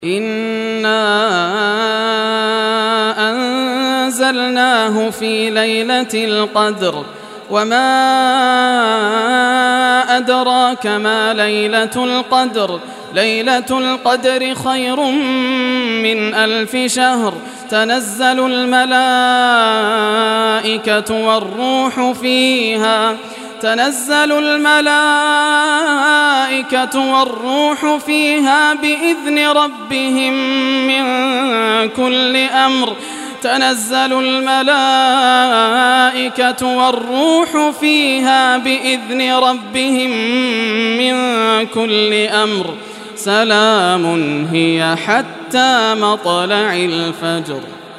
Surah Al-Qadr Recitation by Yasser al Dosari
Surah Al-Qadr, listen or play online mp3 tilawat / recitation in Arabic in the beautiful voice of Sheikh Yasser al Dosari.